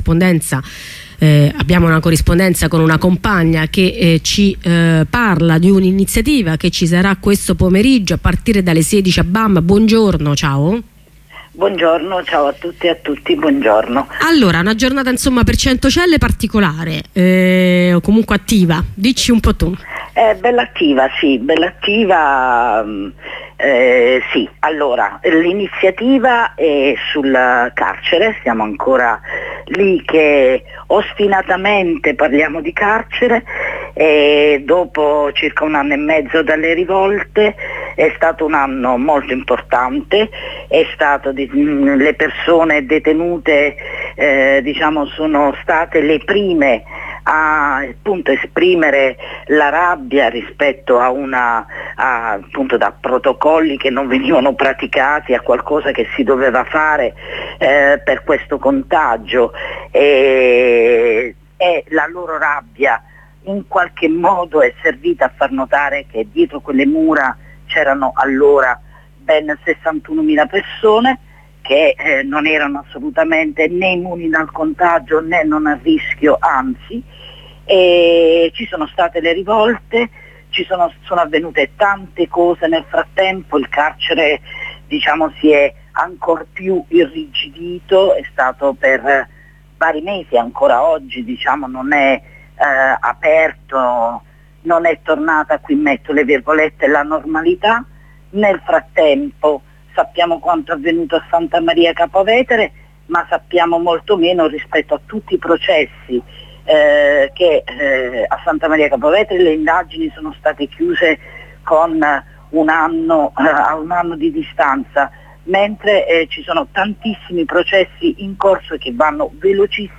Corrispondenza in studio con ospite